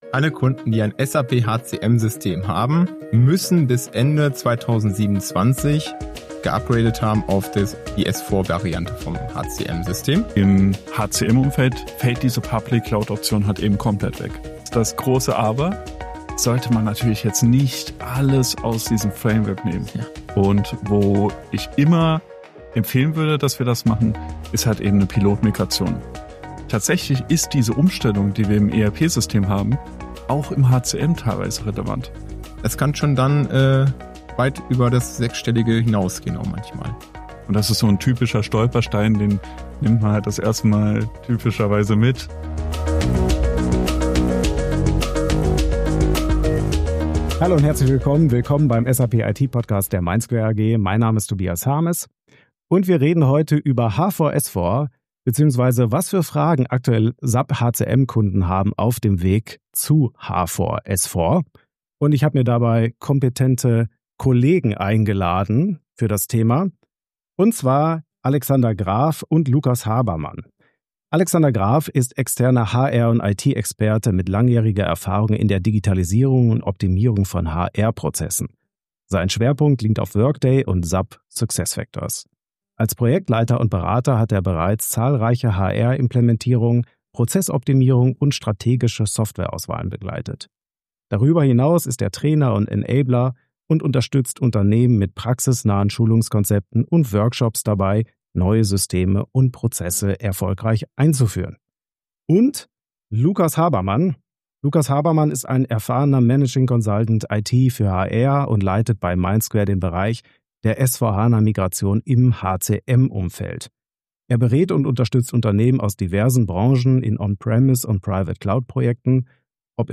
Im Gespräch...